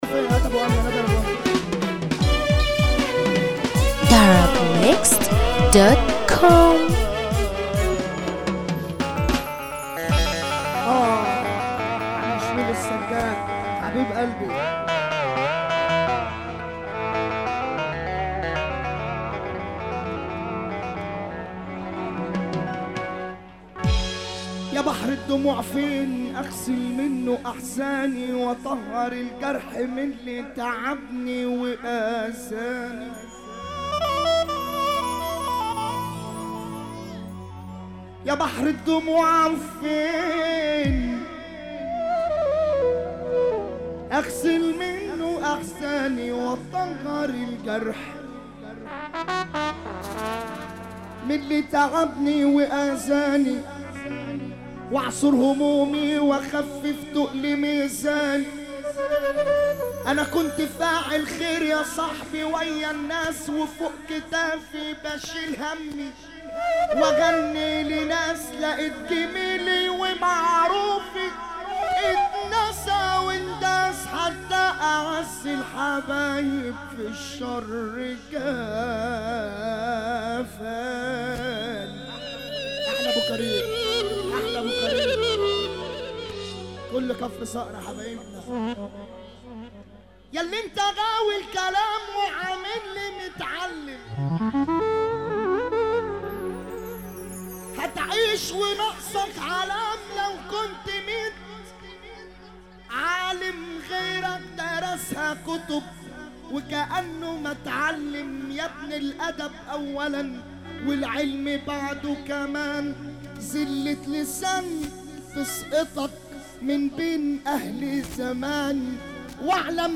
موال
حزينة